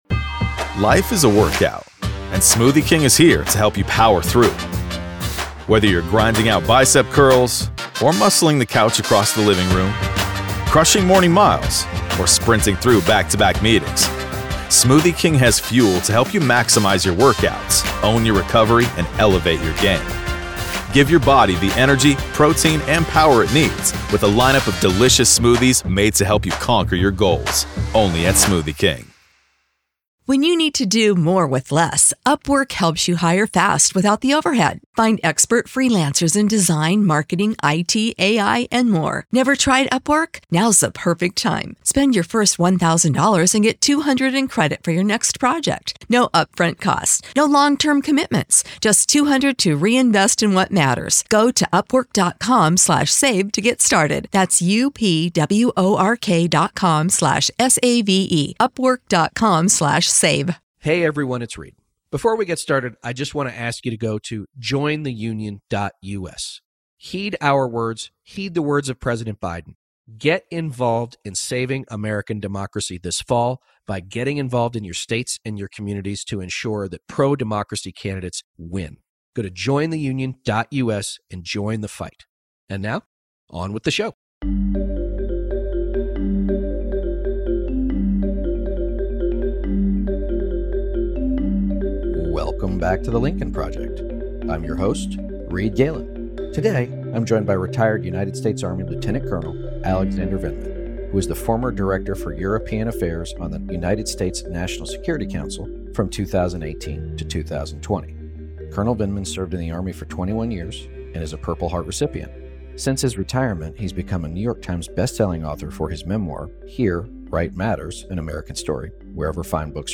is joined by retired United States Army Lieutenant Colonel Alexander Vindman who was the former Director for European Affairs for the United States National Security Council. They discuss the current state of the war in Ukraine, the exponentially increasing desperation from Vladimir Putin and his country’s instability, and why Tucker Carlson and the far-right media apparatus continues to take the side of Russia. Plus, how the heavily conservative Supreme Court is disrupting the balance of the U.S. government.